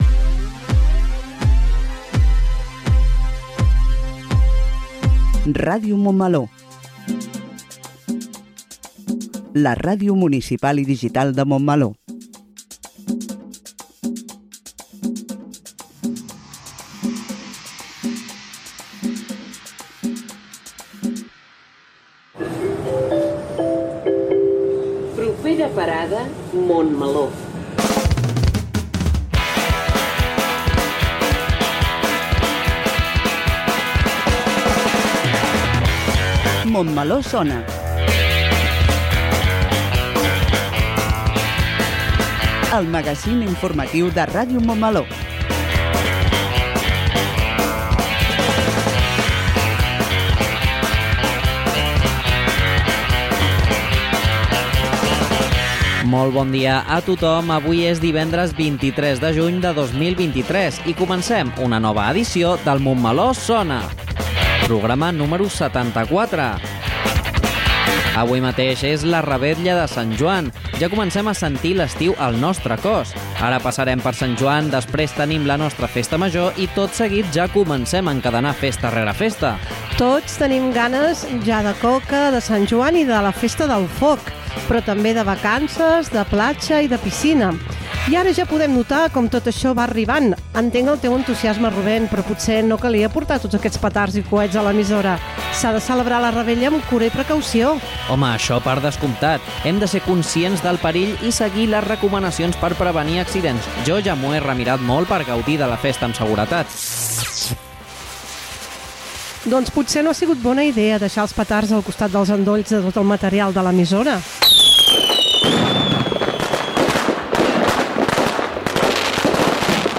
Indicatiu de la ràdio, careta del programa, data, presentació, equip, sumari, indicatiu del programa, secció "Toquem el dos" amb recomanacions sobre activitats LGTBI a Barcelona
Info-entreteniment